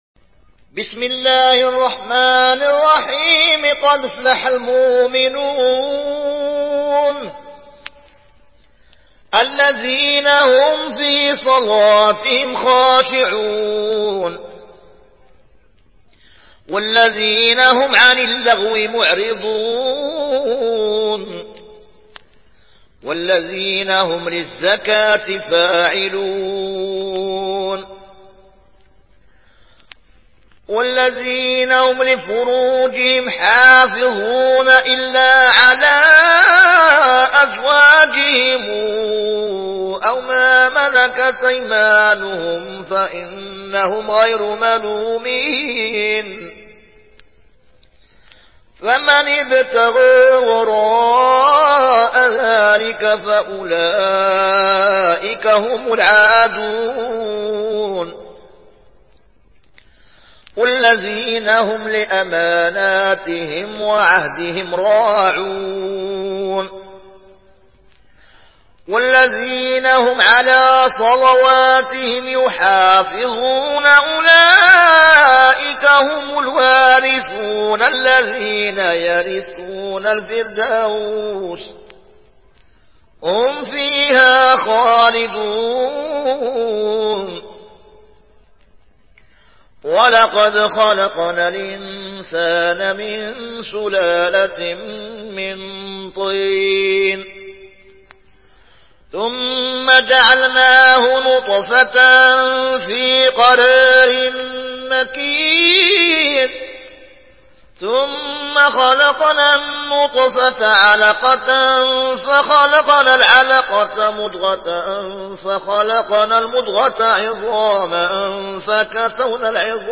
Riwayat Warch an Nafi